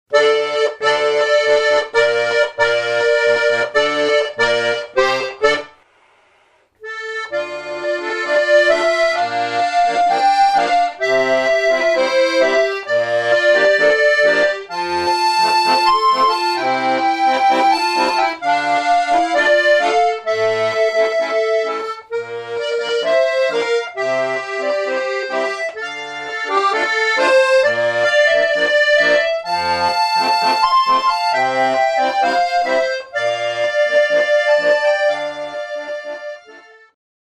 tweestemmige composities